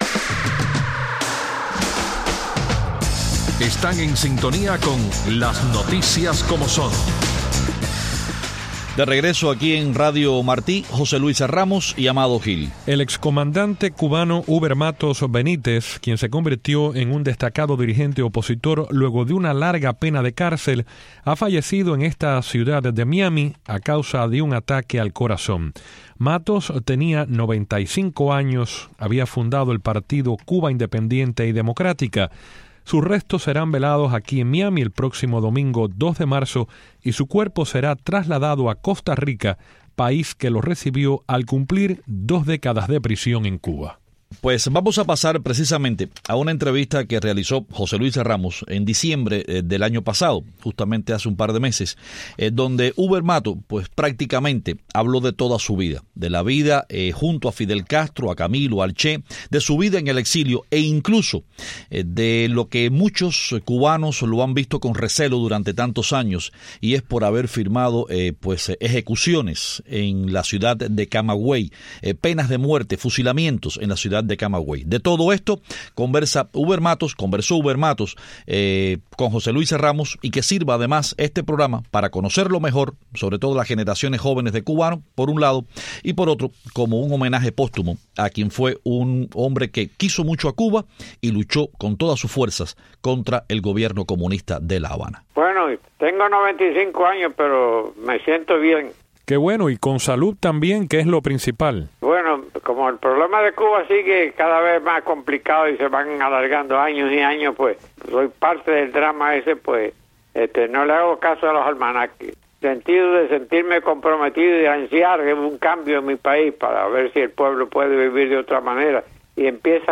De nuestros archivos rescatamos una entrevista con el excomandante guerrillero.